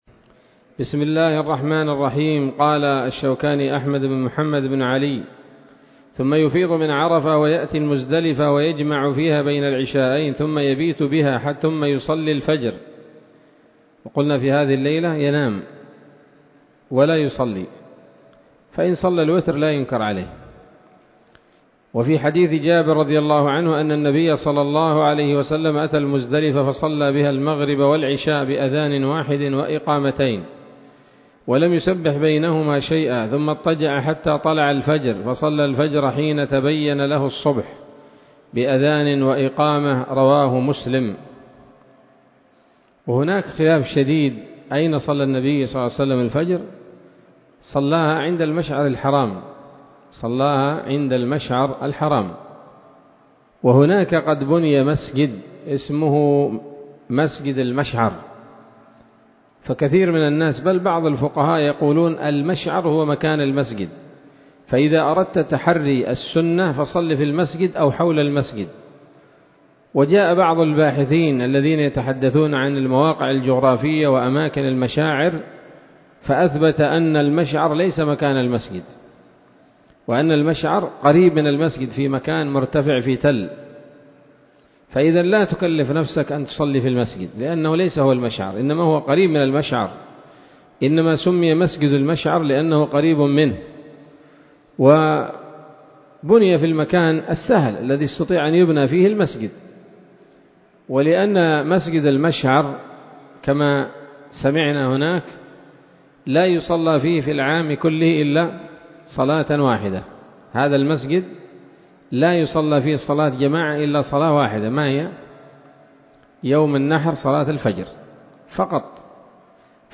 الدرس العشرون من كتاب الحج من السموط الذهبية الحاوية للدرر البهية